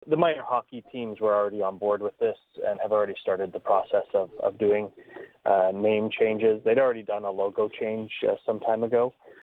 Mayor of Brighton Brian Ostrander.